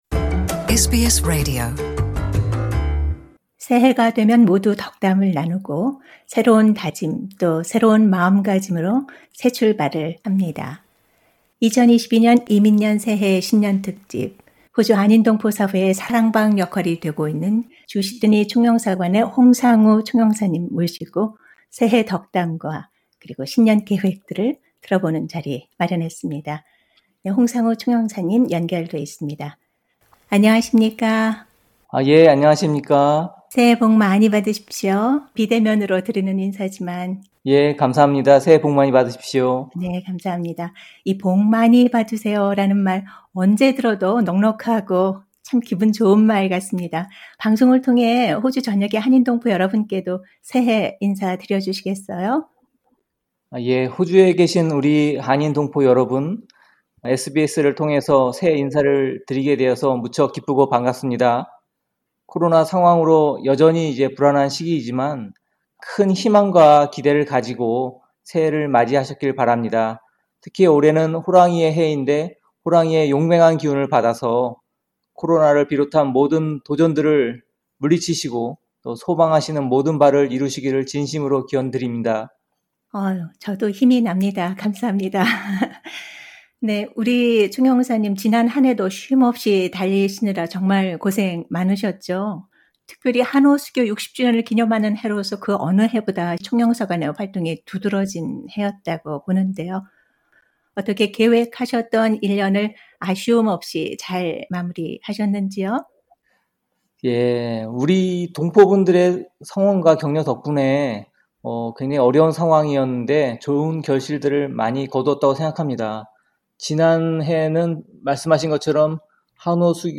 새해특별대담: 홍상우 주시드니 총영사…"2022, 한호 포괄적 동반관계 구체화의 해"